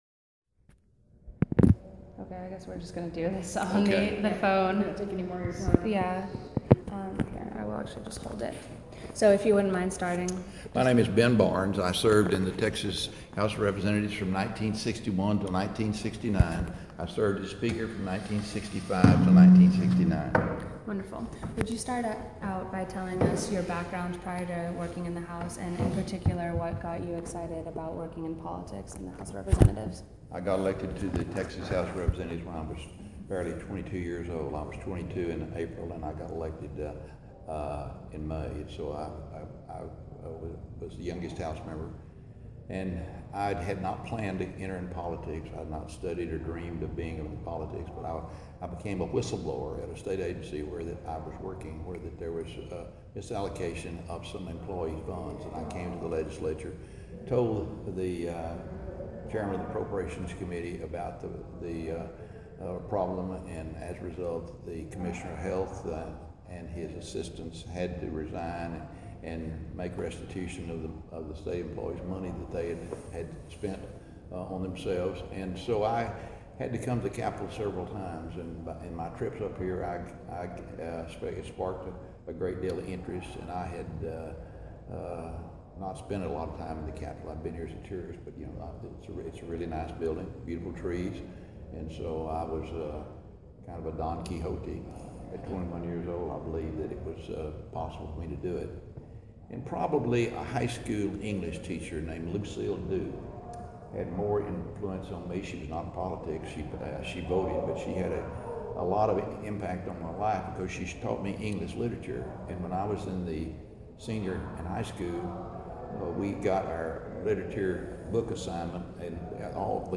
Oral history interview with Ben Barnes, 2015.